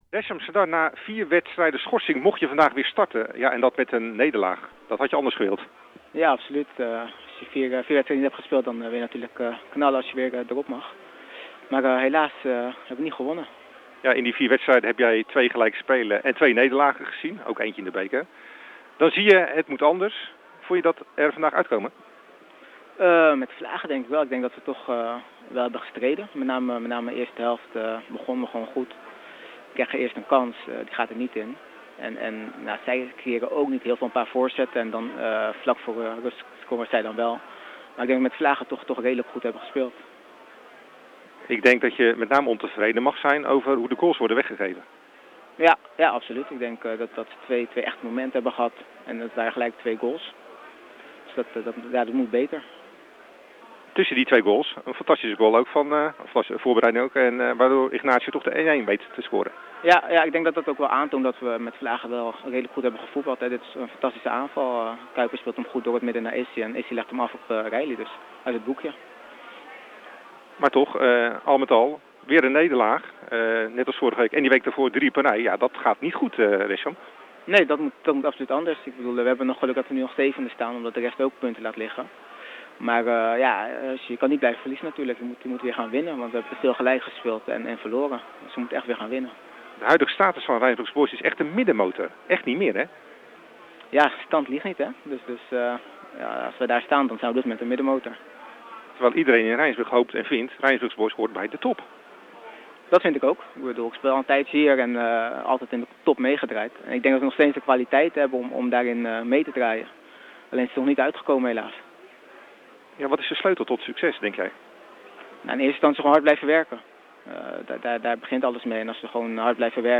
VIDEO: Nabeschouwing Rijnsburgse Boys – HHC Hardenberg